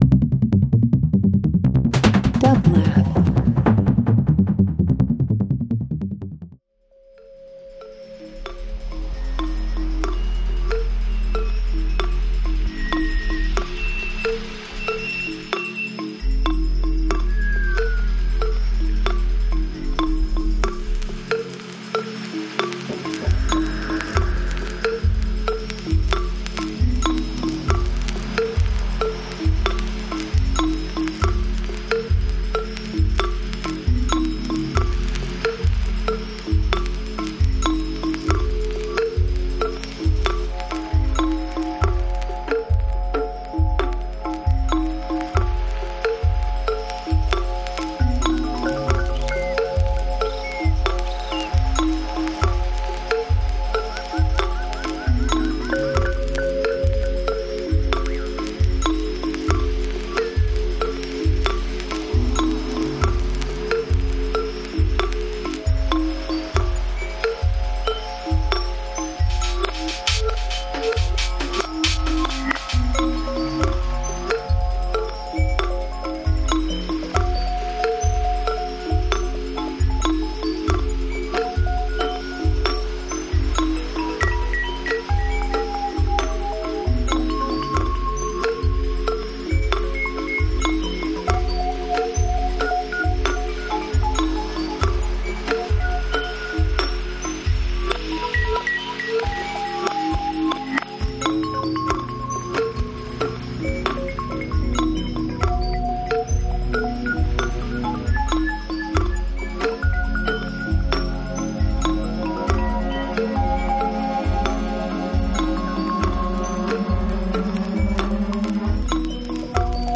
Beats Dance Techno